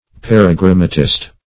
Paragrammatist \Par`a*gram"ma*tist\, n.
paragrammatist.mp3